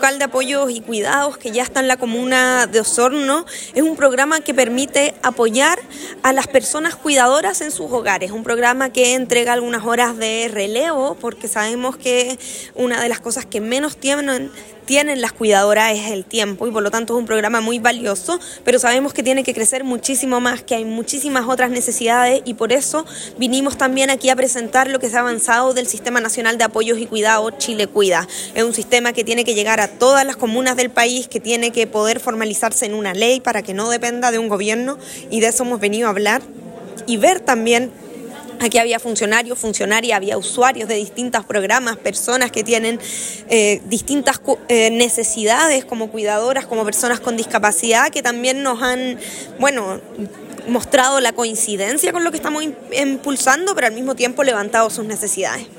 Durante su visita a la comuna de Osorno, la Ministra de Desarrollo Social y Familia, Javiera Toro, participó de un encuentro en el marco del Día Internacional de la Erradicación de la Pobreza, donde se reunieron con la red de protección social de la provincia para dialogar con beneficiarios y beneficiarias de los programas ejecutados por el Ministerio.
En su intervención, la ministra detalló los esfuerzos del gobierno por fortalecer la red de apoyo social, anunciando además un aumento en el presupuesto destinado a temas de cuidados para 2025, lo que permitirá ampliar la cobertura y mejorar la calidad de los servicios ofrecidos a personas en situación de vulnerabilidad.